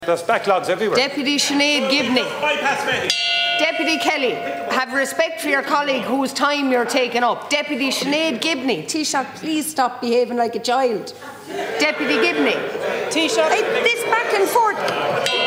Rowdy scenes prompted Ceann Comhairle Verona Murphy to intervene.